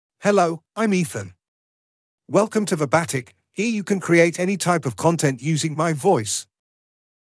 MaleEnglish (United Kingdom)
Ethan is a male AI voice for English (United Kingdom).
Voice sample
Ethan delivers clear pronunciation with authentic United Kingdom English intonation, making your content sound professionally produced.